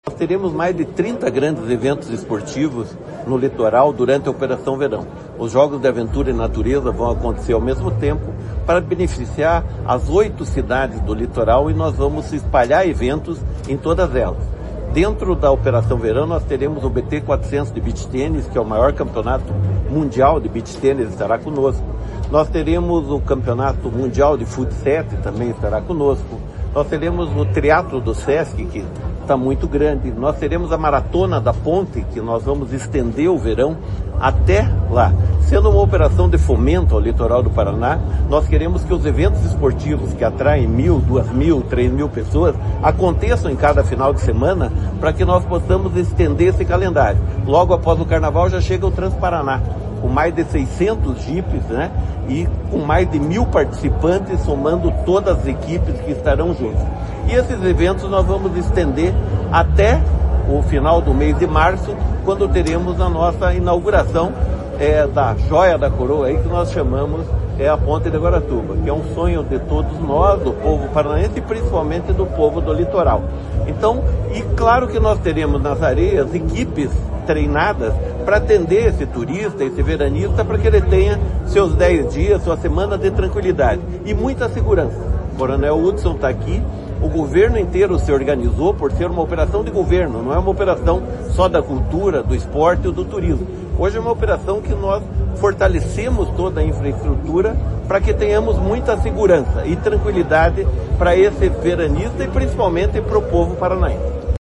Sonora do secretário do Esporte, Hélio Wirbiski, sobre o Verão Maior Paraná 2025/2026